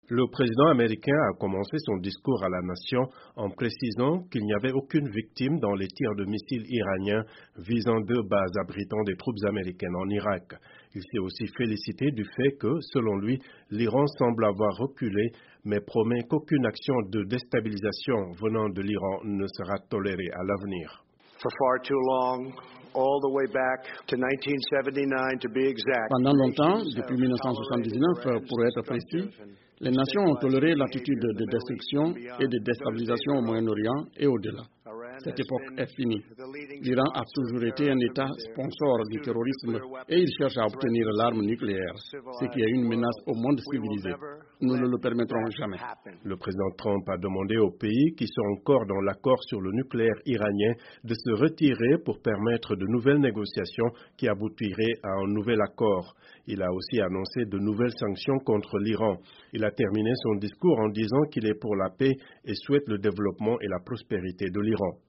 Le président Donald Trump, d'un ton mesuré mais ferme, affirme que l’Iran semble avoir reculé. Il a néanmoins annoncé que de nouvelles sanctions seront prises contre Téhéran.